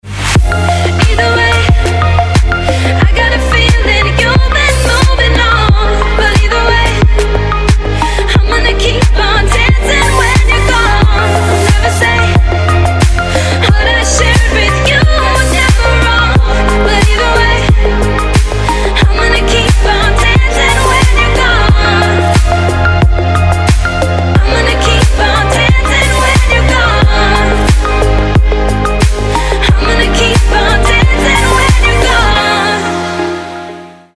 красивые
женский вокал
Electronic
indie pop